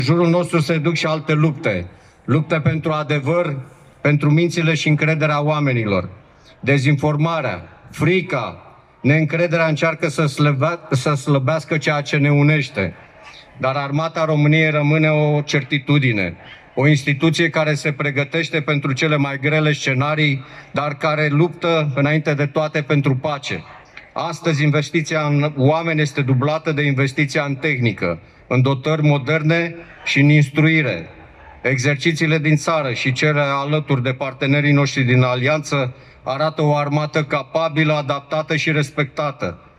Totodată, în discursul său, Generalul Gheorghiță Vlad, Şeful Statului Major al Apărării, a spus astăzi că „trăim vremuri în care se vorbește mult despre război, dar mai puțin despre curaj”.